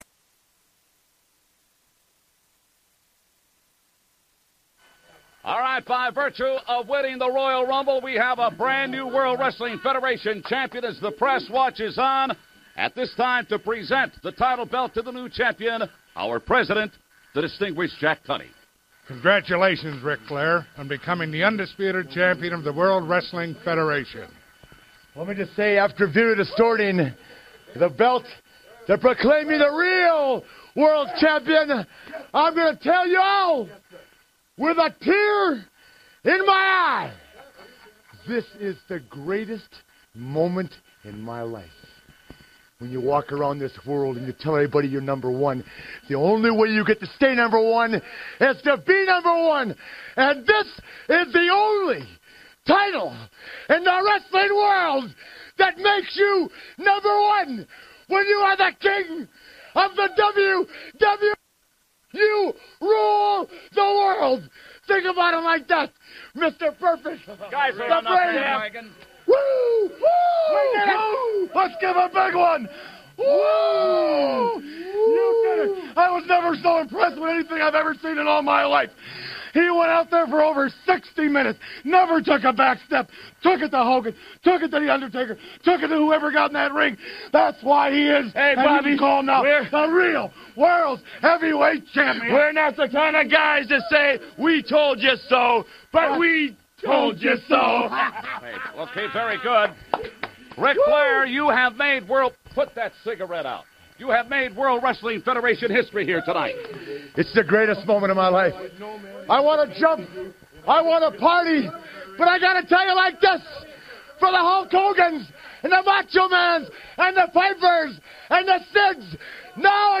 The boys are joined in studio